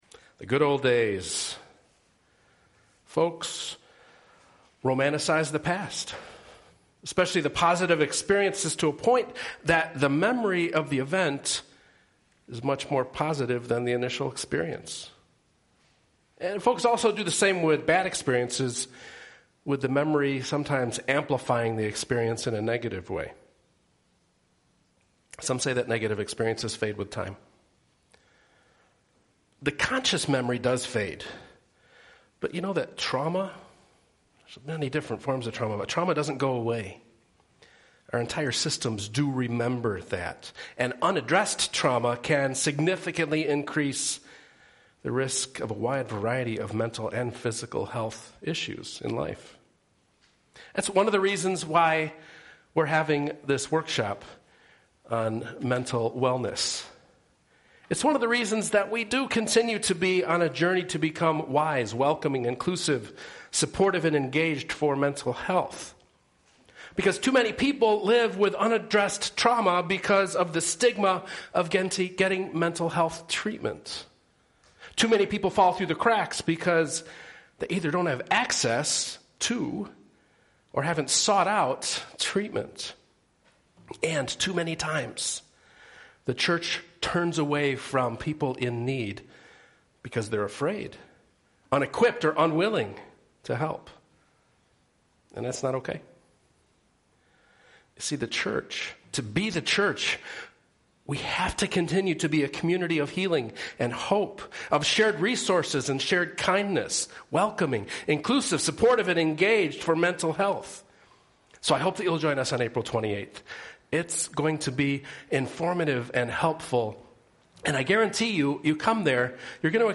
New Sermon podcast from this past Sunday (4-7-19) at St. Paul's UCC in Downers Grove, IL Isaiah 43:16-21